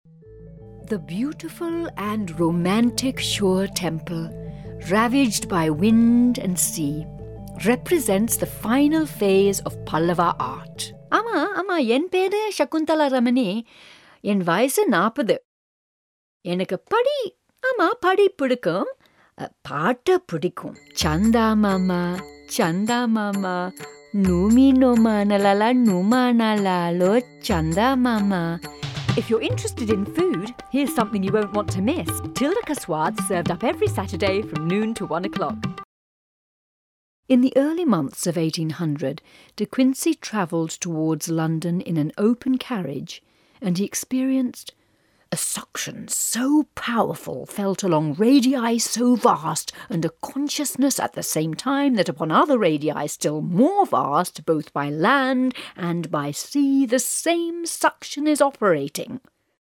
Female / 40s, 50s / English, Tamil / Southern
Showreel